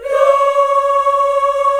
AFROLA C#5-R.wav